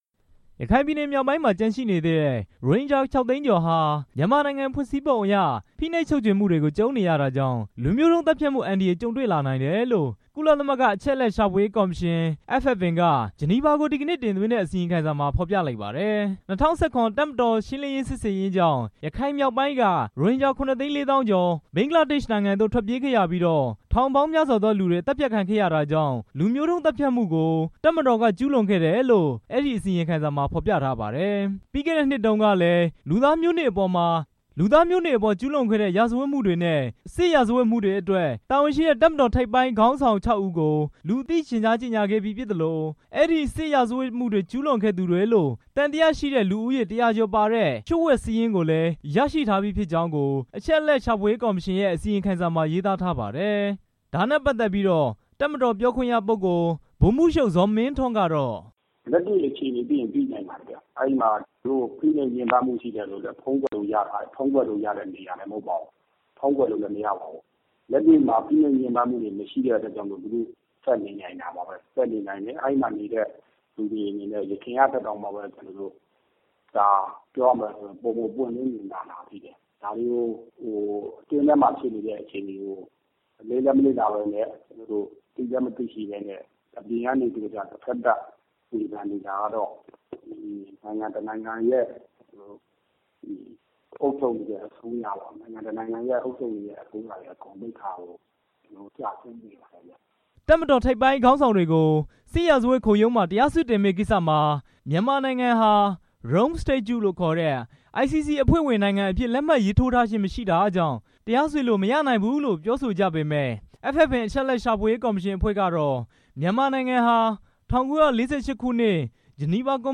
တပ်မတော်ပြောခွင့်ရပုဂ္ဂိုလ် ဗိုလ်မှူးချုပ် ဇော်မင်းထွန်းကတော့ FFM အစီရင်ခံစာအပေါ် အခုလို တုံ့ပြန်ပါတယ်။တပ်မတော်သတင်းမှန်ပြန်ကြားရေးအဖွဲ့က ဗိုလ်မှူးချုပ် ဇော်မင်းထွန်း